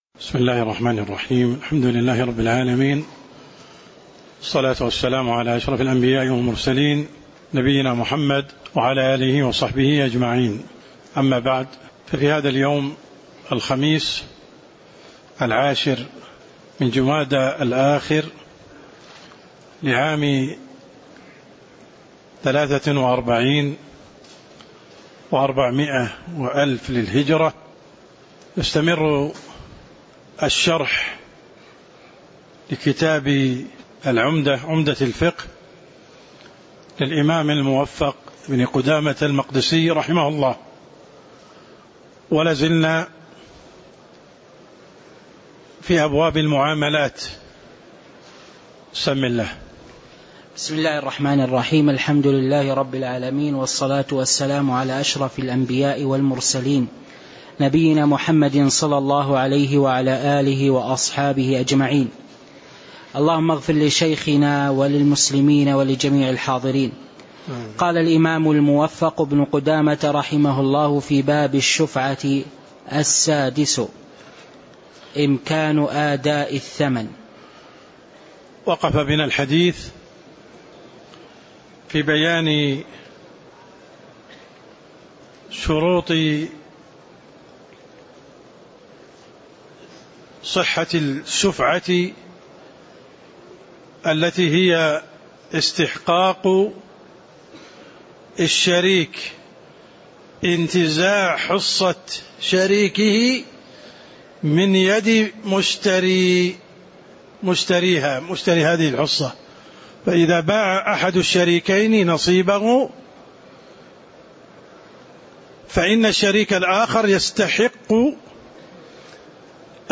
تاريخ النشر ١٠ جمادى الآخرة ١٤٤٣ هـ المكان: المسجد النبوي الشيخ: عبدالرحمن السند عبدالرحمن السند قوله: السادس إمكان أداء الثمن (03)